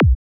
FLC-Kick-Parandroid-B.wav